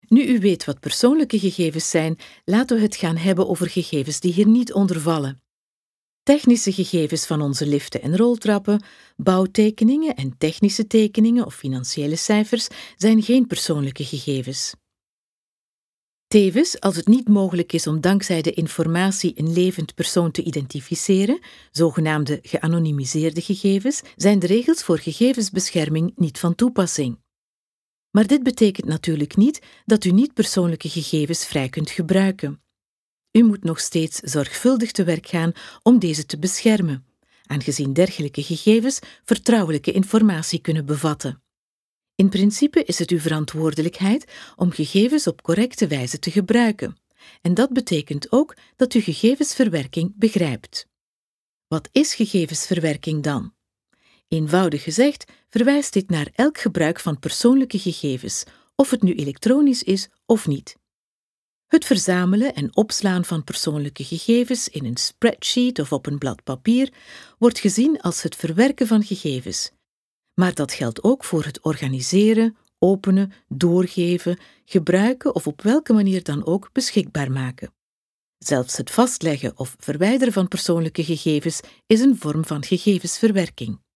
Sprechprobe: eLearning (Muttersprache):
TV-Interpreter Professional voice talent Flemish and European English